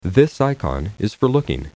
However, there are voiced versions of all of them in resource.aud: